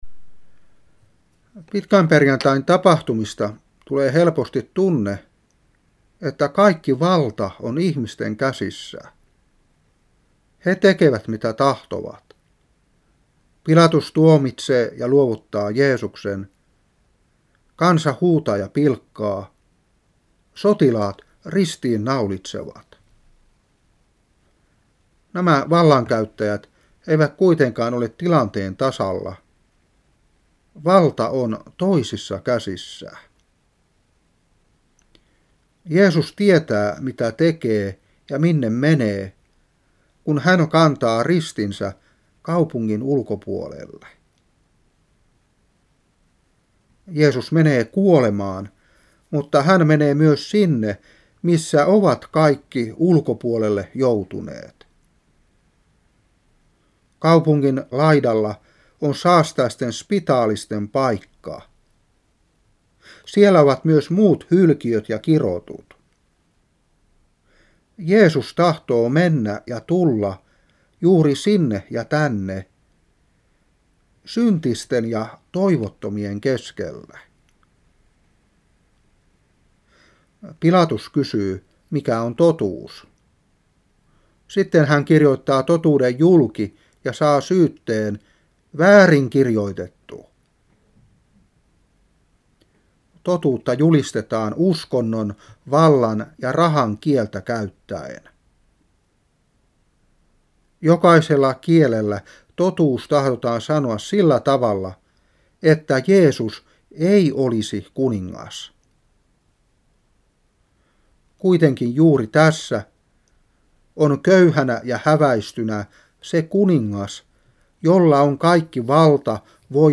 Saarna 2008-3.